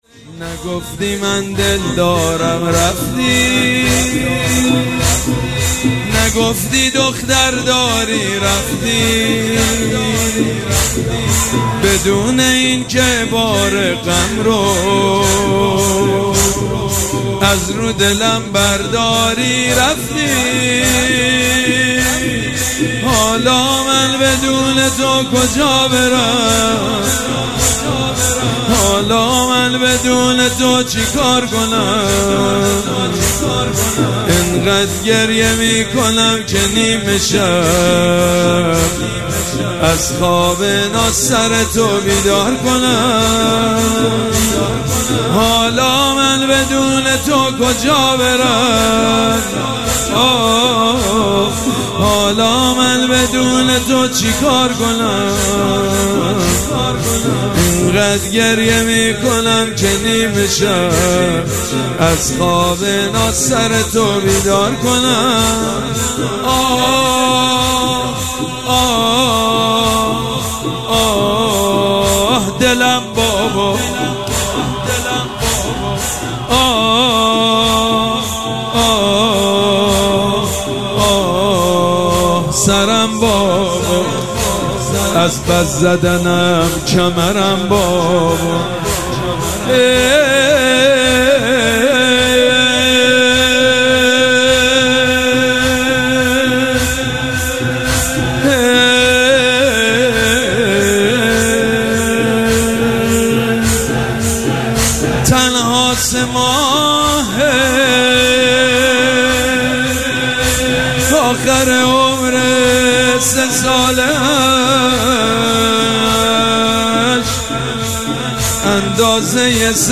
شب سوم مراسم عزاداری اربعین حسینی ۱۴۴۷
حاج سید مجید بنی فاطمه